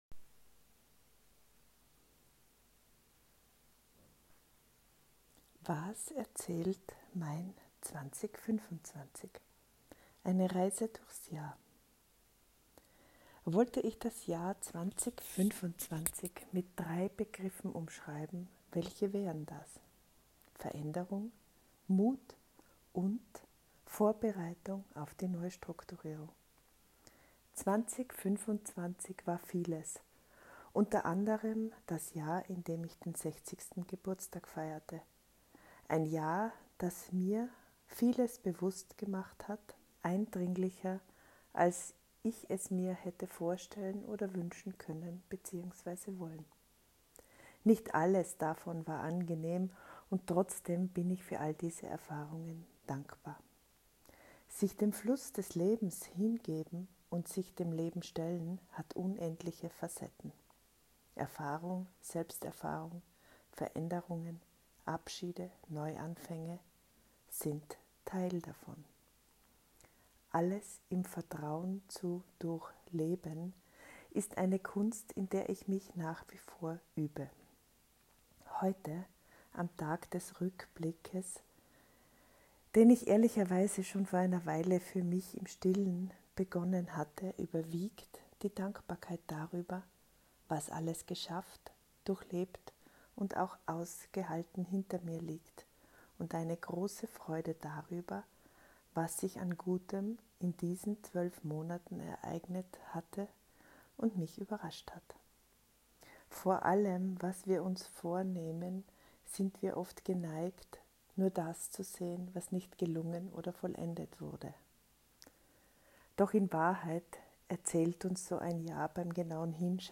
Alles auch wieder zum Hören (ich lese es Dir vor)